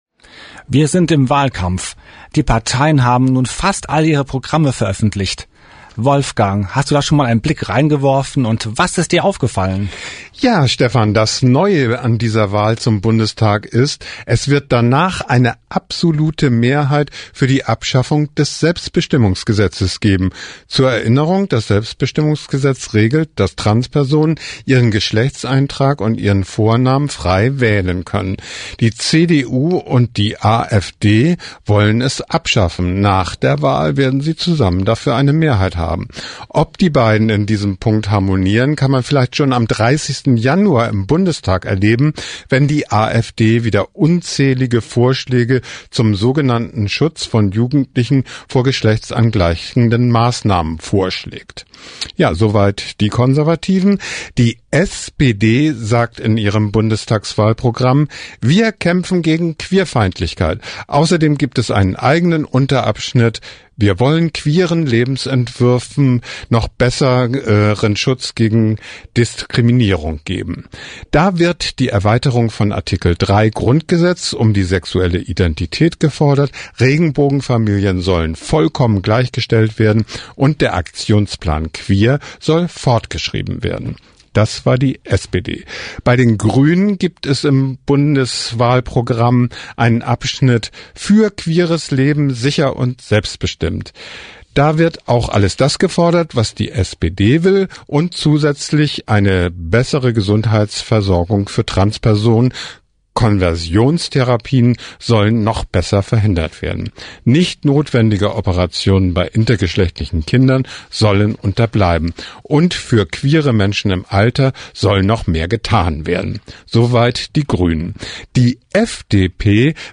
Pink Channel Nachrichten